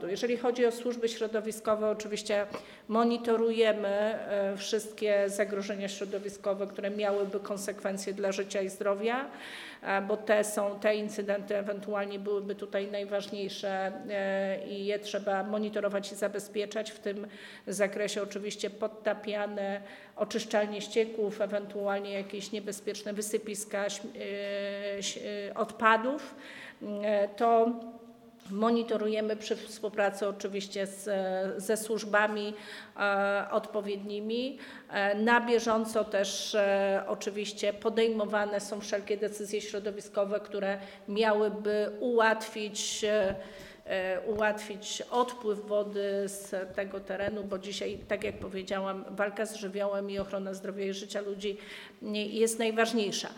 Paulina Hennig – Kloska przedstawiła też szczegóły dotyczące zagrożeń środowiskowych, które mogą nastąpić w skutek powodzi.